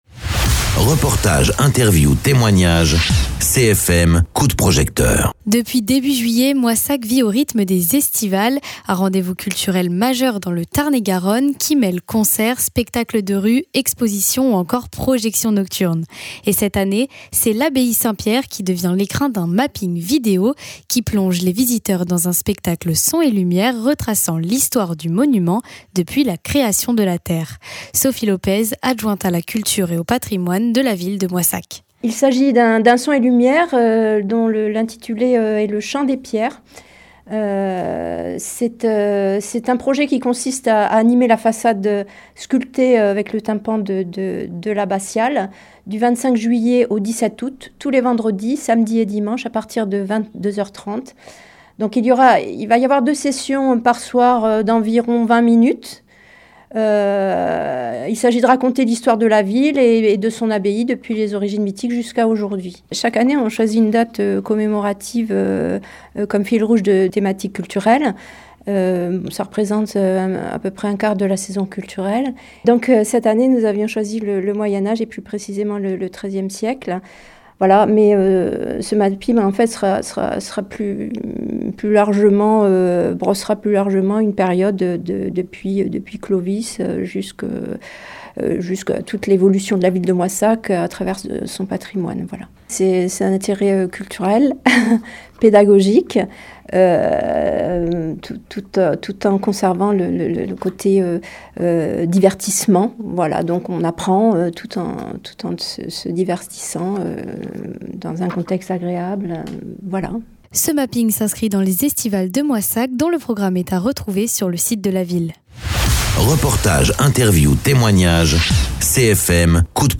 Interviews
Invité(s) : Sophie Lopez, adjointe à la culture et au patrimoine à la ville de Moissac